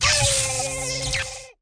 Player Zap Death Sound Effect
Download a high-quality player zap death sound effect.
player-zap-death.mp3